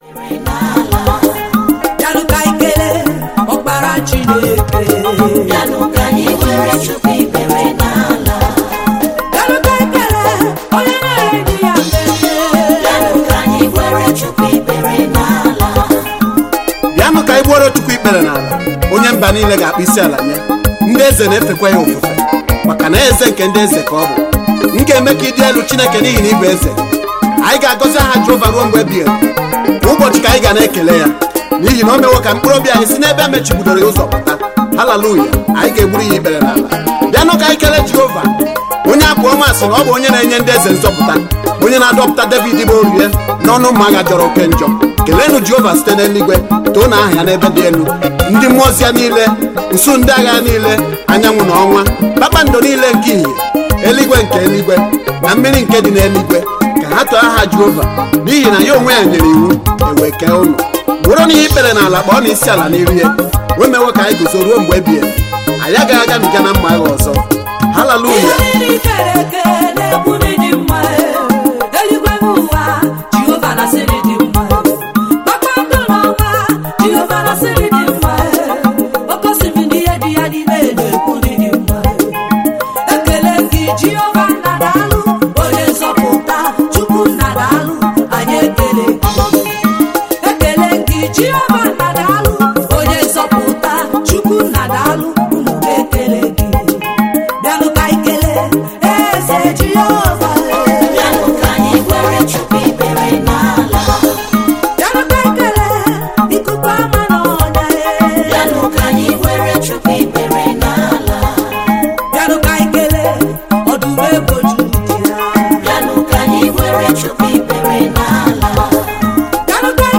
Igbo Gospel music